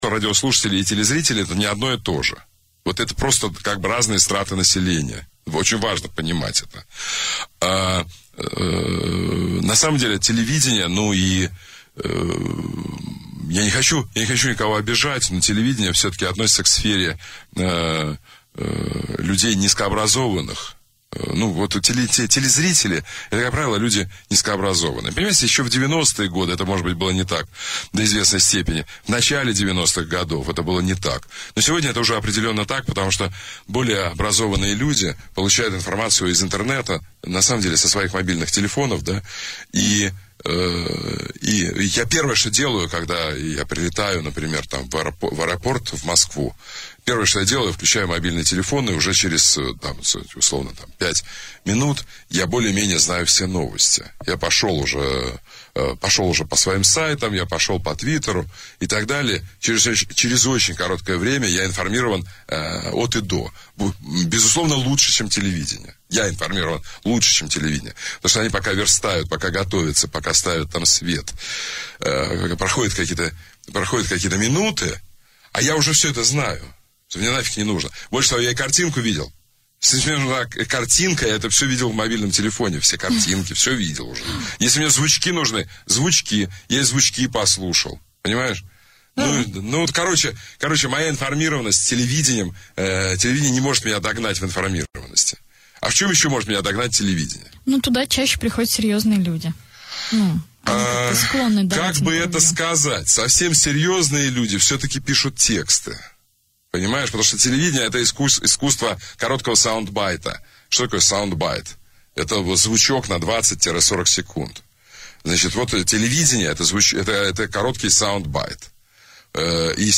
Ведущий радиостанции Говорит Москва Сергей Доренко
Перед ответственным интервью с министром иностранных дел России Сергей Доренко в утреннем шоу на радиостанции "Говорит Москва" поблагодарил Сергея Лаврова за выбор радио для общения с журналистами. Получился весьма любопытный монолог о медиа и взаимоотношениях радио и телевидения.
Формат: Talk&News